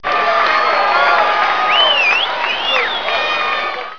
crowd1.wav